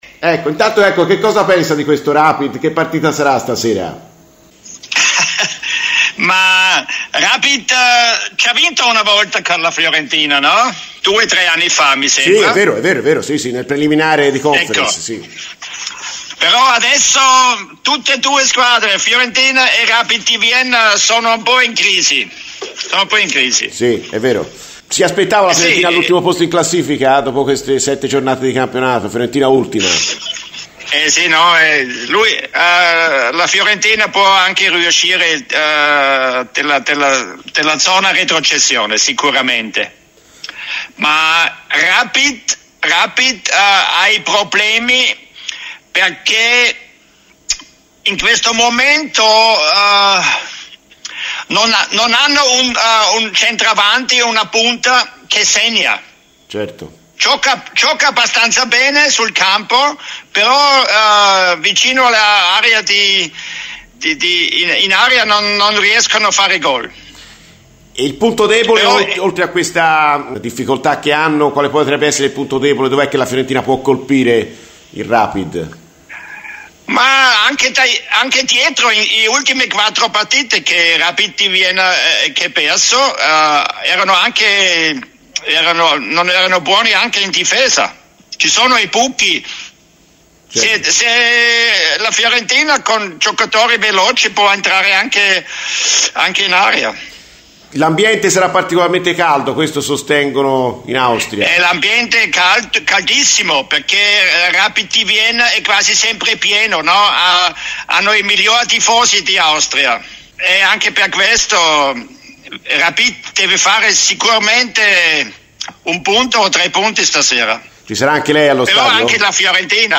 Walter Schachner, ex calciatore austriaco di Cesena, Torino e Avellino, tra le altre, ha parlato delle sue sensazioni sulla partita di stasera tra Rapid Vienna e Fiorentina a Radio FirenzeViola nel corso di "Viola amore mio", ecco le sue parole: "Adesso sono due squadre in crisi, anche se il Rapid una volta ha vinto con la Fiorentina".